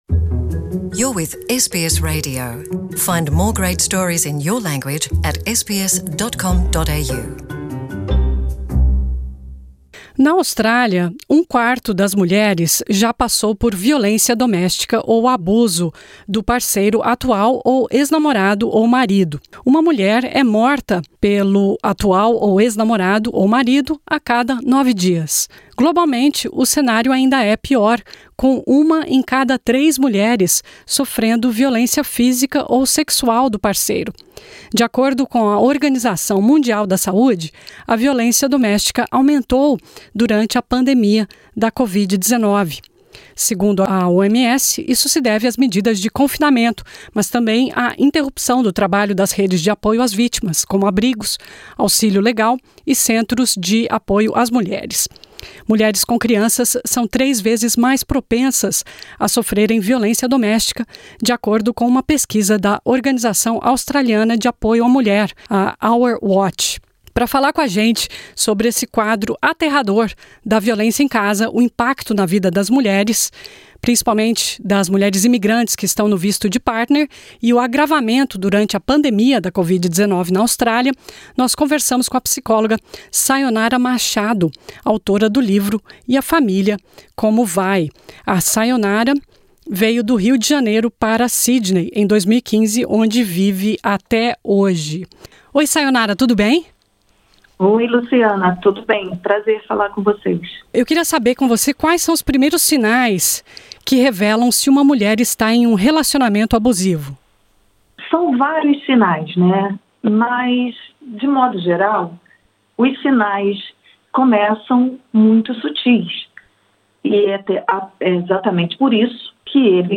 A seguir os principais trechos da entrevista.